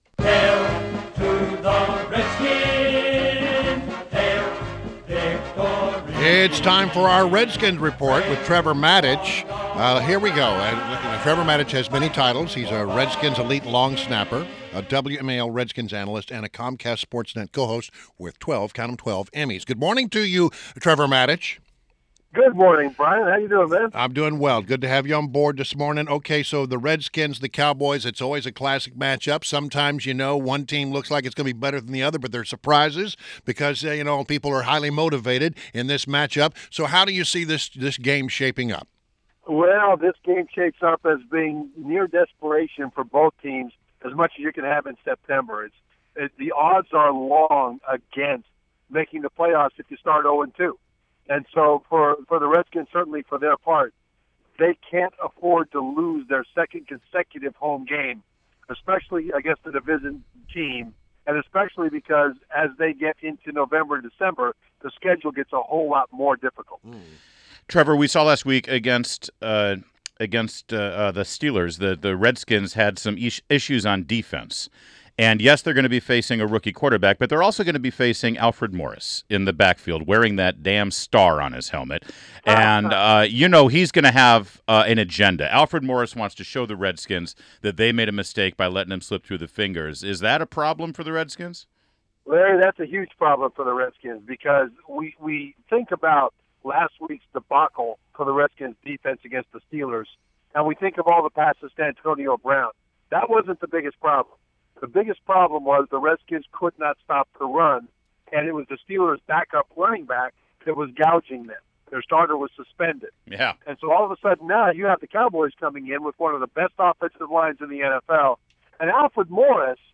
INTERVIEW -- TREVOR MATICH -- Redskins elite long snapper, WMAL's Redskins analyst and Comcast SportsNet co-host